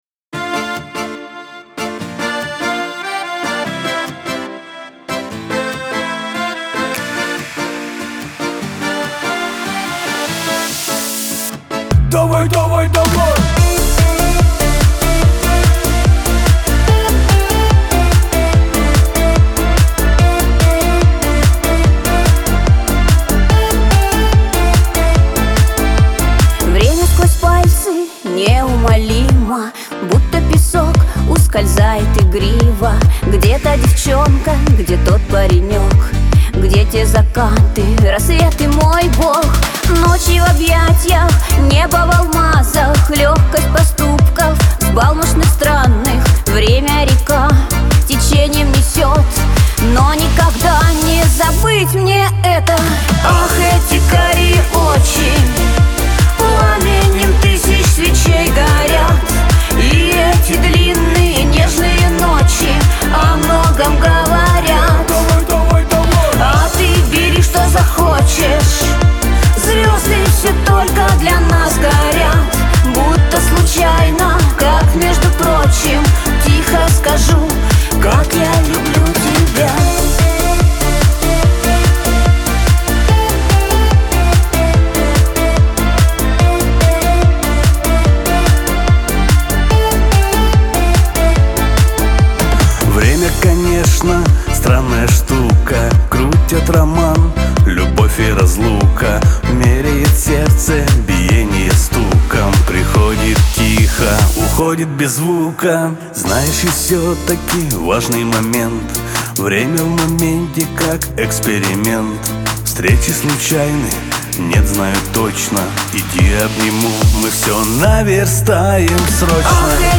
pop
диско
дуэт